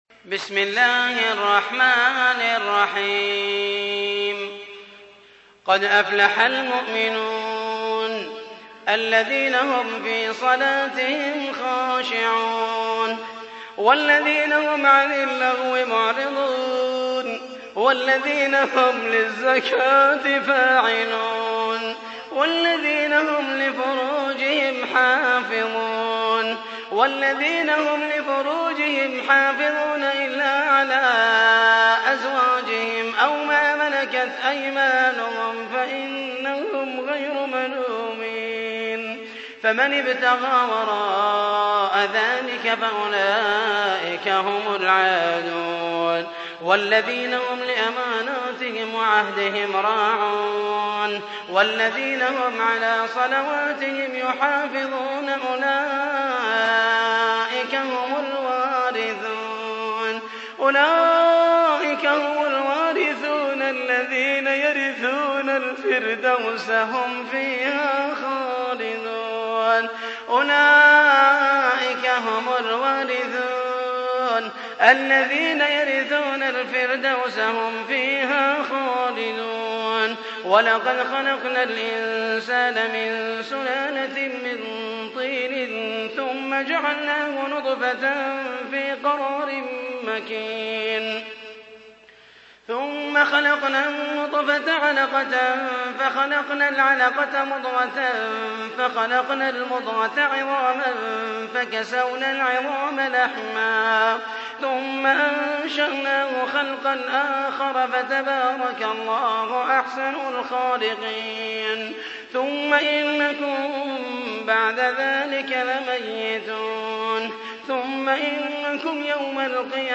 تحميل : 23. سورة المؤمنون / القارئ محمد المحيسني / القرآن الكريم / موقع يا حسين